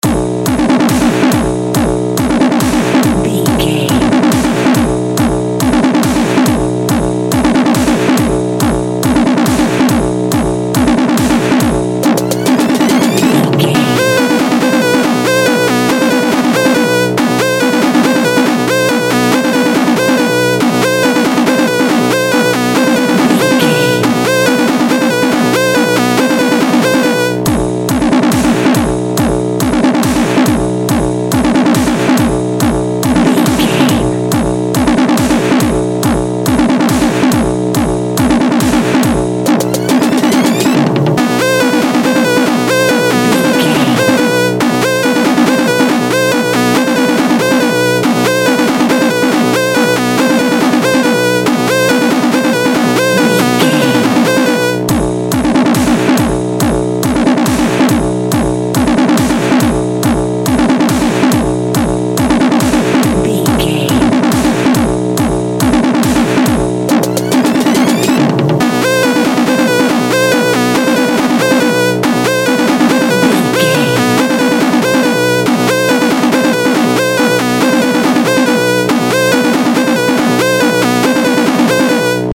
Dubstep Video Game Music.
Epic / Action
Fast paced
Phrygian
aggressive
dark
driving
intense
drum machine
synthesiser
electronic
synth lead
synth bass